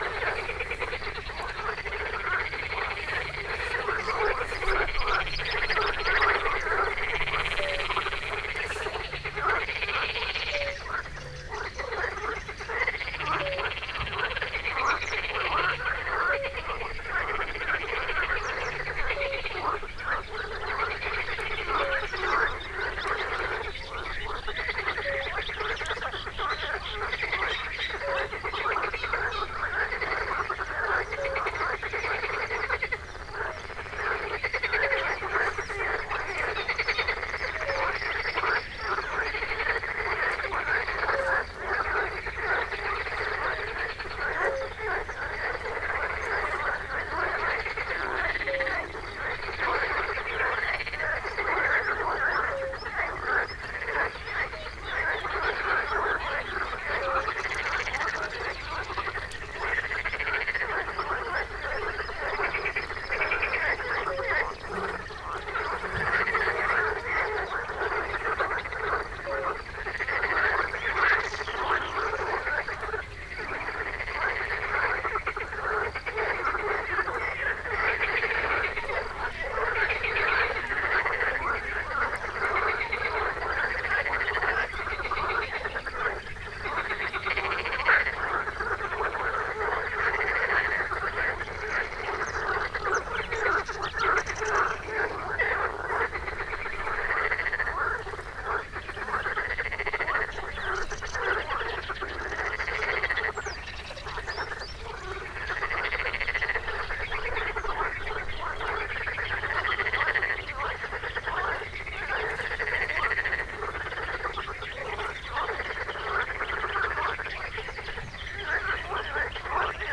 nagykorus_hortobagyihalastavak_sds06.33.WAV